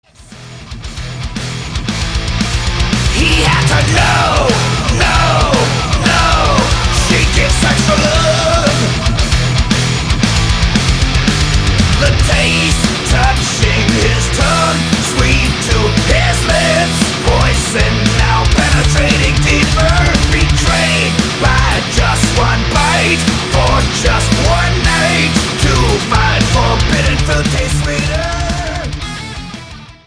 Studio: Lattitude South Studios, Leiper's Fork, Tennessee
Genre: Thrash Metal, Heavy Metal